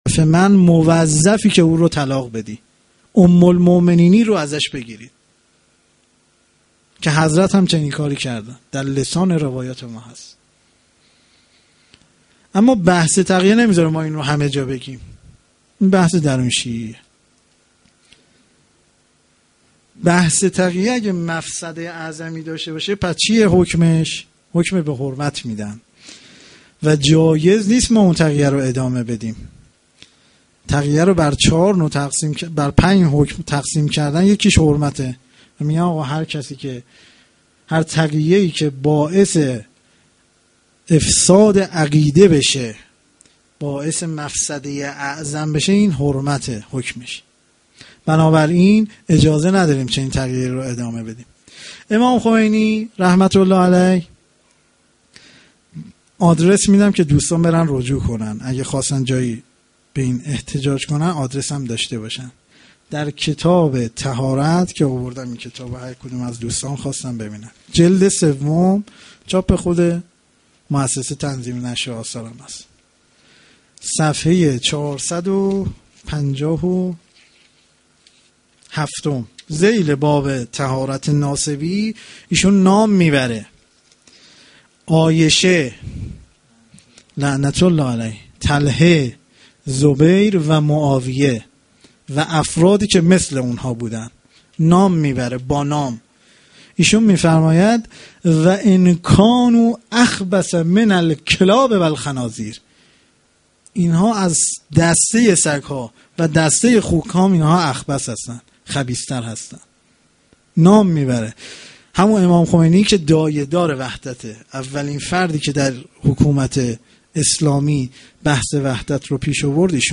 fa93-zohre-shahadat-sokhanrani.mp3